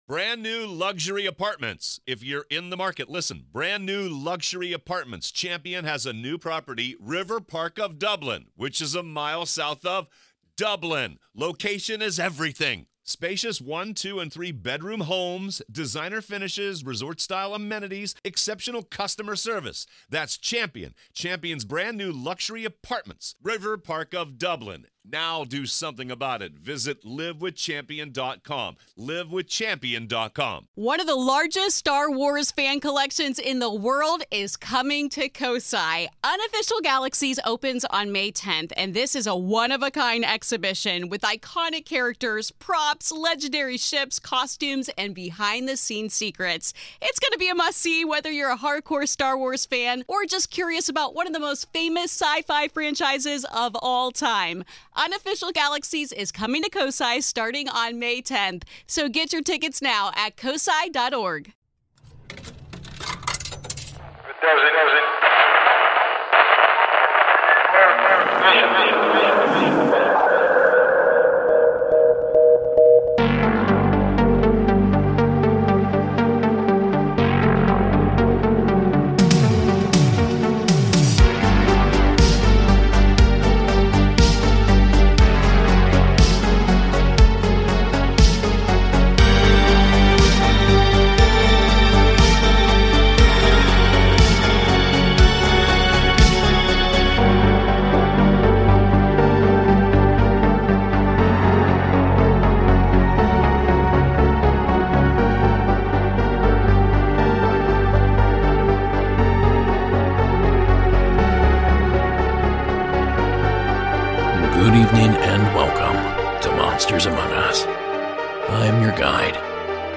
Season 15 Episode 8 of Monsters Among Us Podcast, true paranormal stories of ghosts, cryptids, UFOs and more, told by the witnesses themselves.